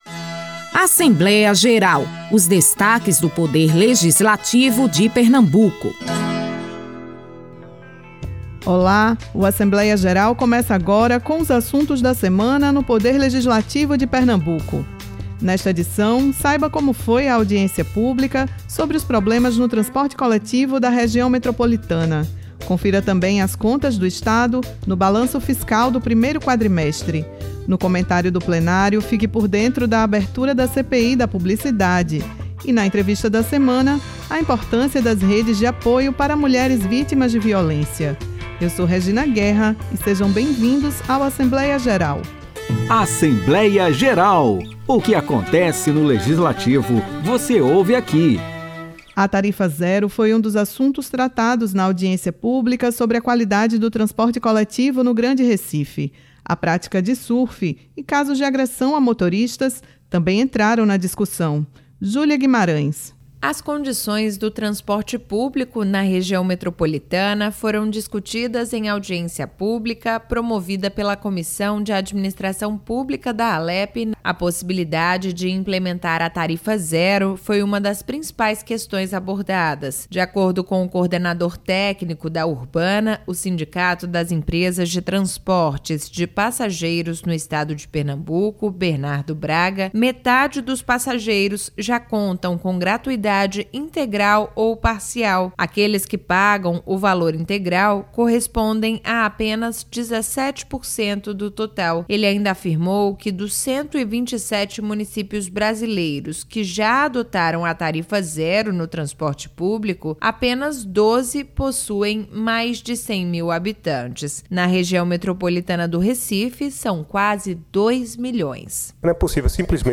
O programa Assembleia Geral é uma produção semanal da Rádio Alepe , com os destaques do Legislativo pernambucano.